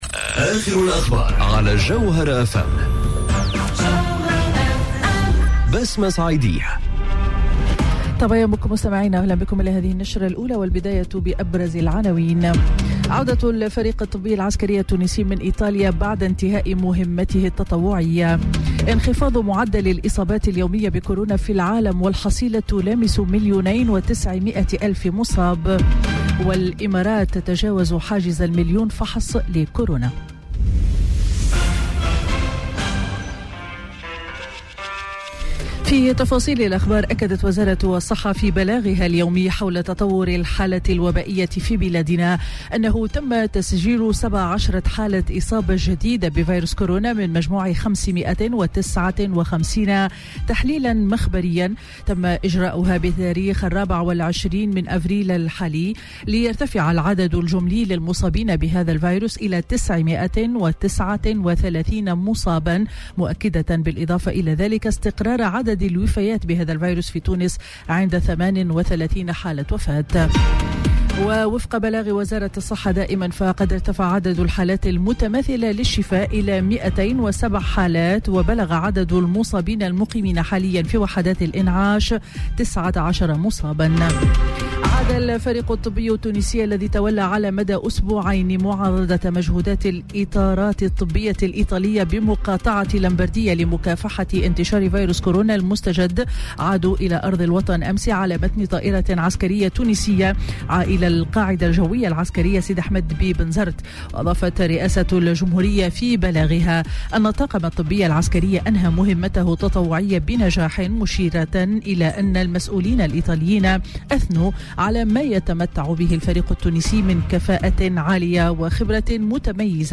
نشرة أخبار السابعة صباحا ليوم الأحد 26 أفريل 2020